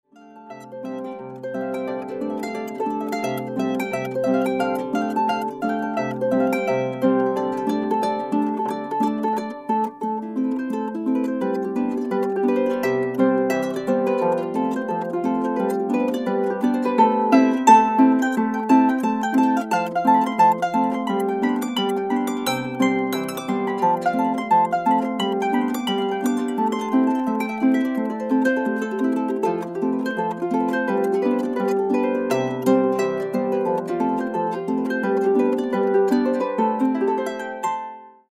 (Celtic harp)  4'063.76 MB1.70 Eur